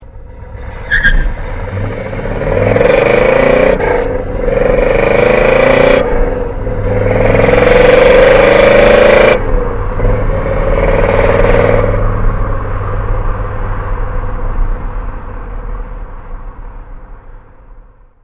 Yes, that is a built 355 (.030 over 350 SBC).
Here's a taste of the 3-chamber 2.25" Flowmasters. This was recorded directly into my Toshiba notebook.